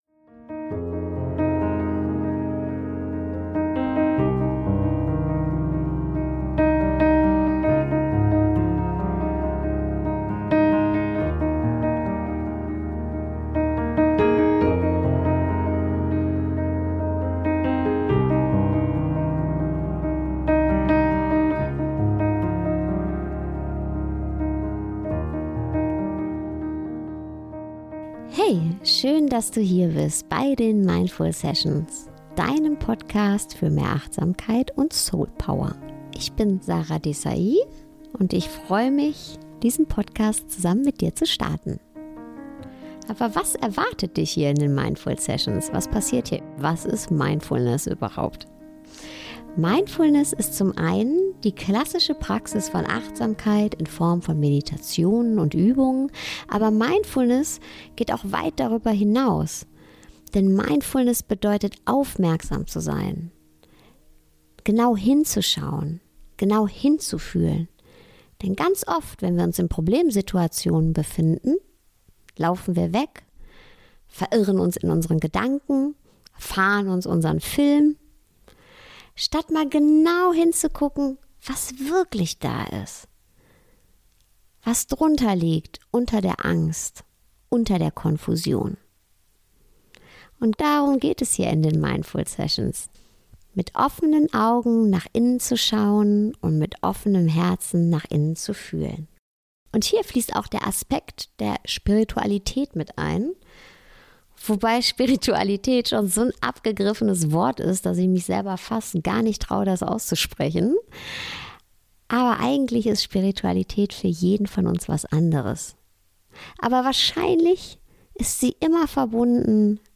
Auf der Suche nach dem, was wirklich zählt - Ein Gespräch